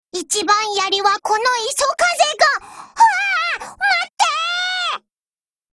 Cv-30160_warcry.mp3